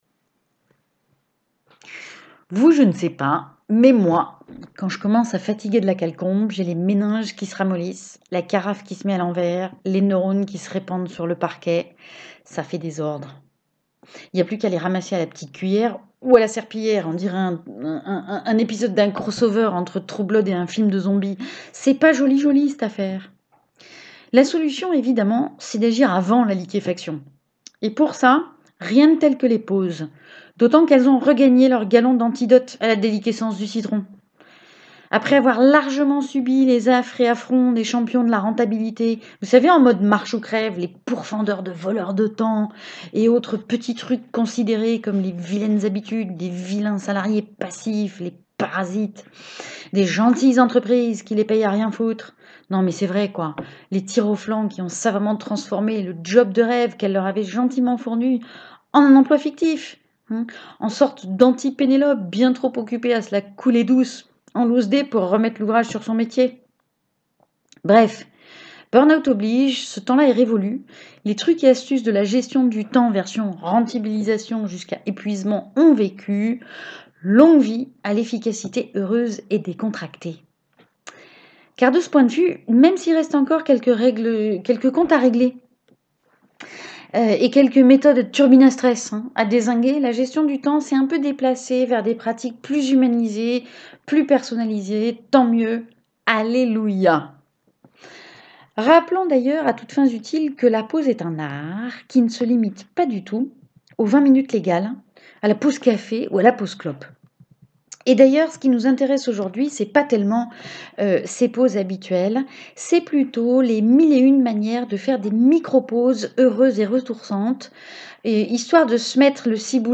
A voix haute